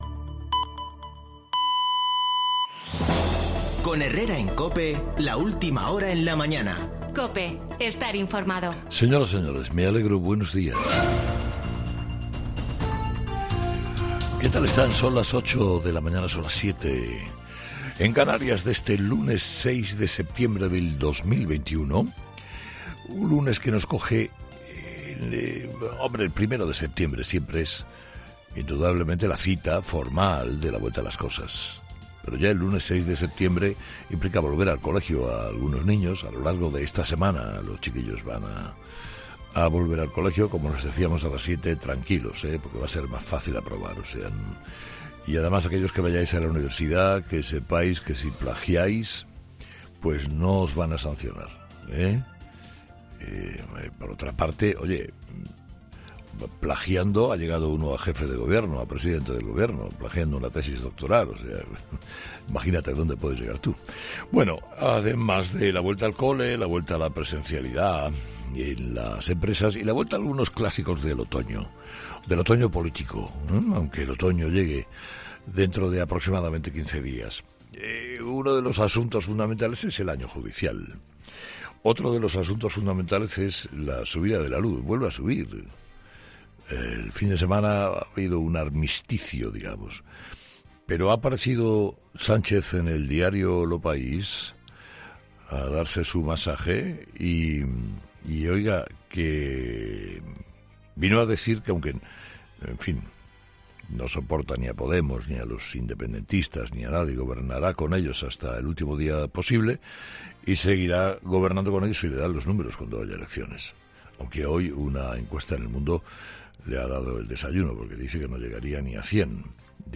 El comienzo del año judicial o las promesas de Pedro Sánchez en su particular "Aló Presidente" son algunos de los temas que aborda Herrera en su editoral de este lunes